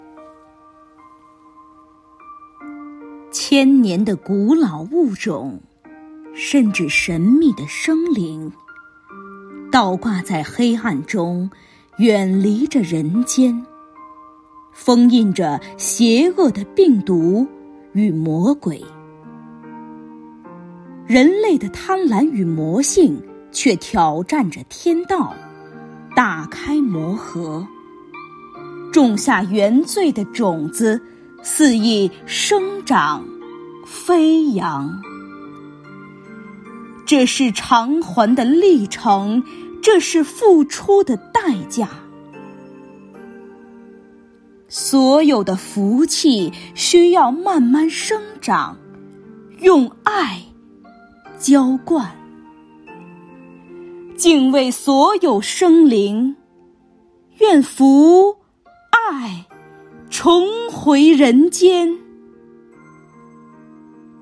为声援战斗在一线的工作人员，鼓舞全区人民抗击疫情的信心和决心，丰南文化馆、丰南诗歌与朗诵协会继续组织诗歌与诵读工作者、爱好者共同创作录制诵读作品。
朗诵